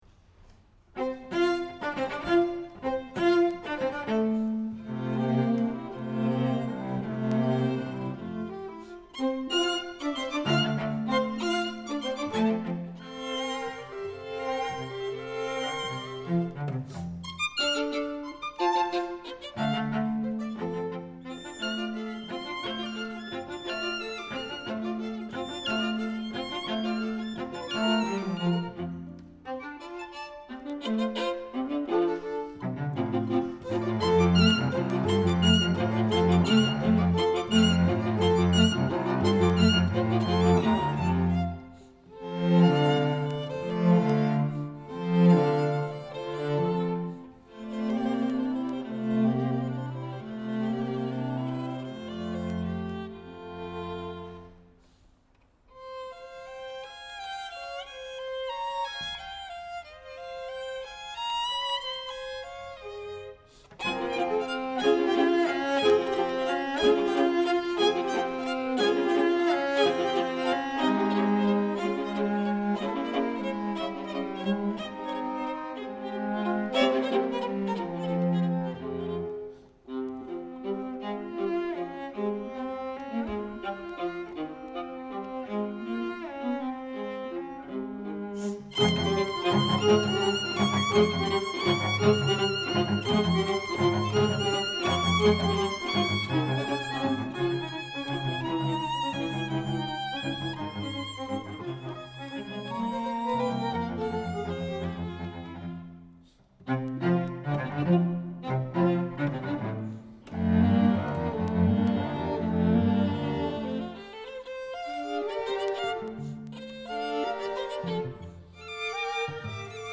Chamber Groups
Molto vivace